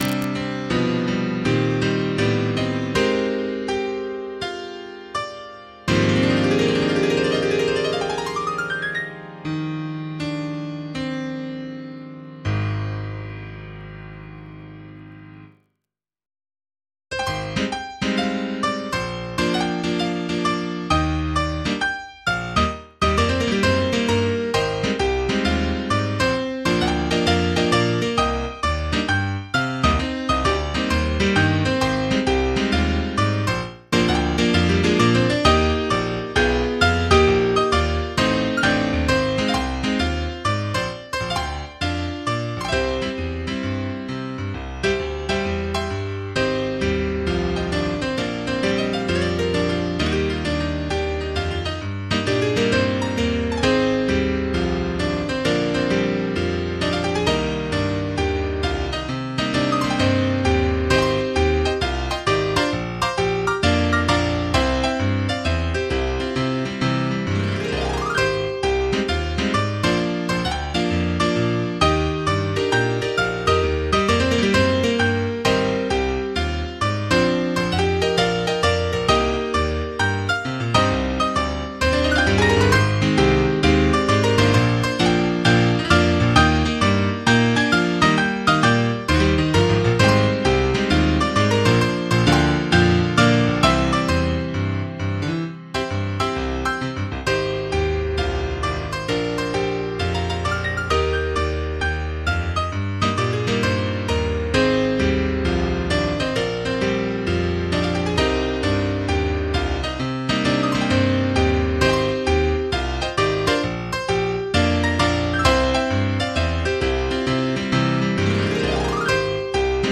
MIDI 17.9 KB MP3 (Converted)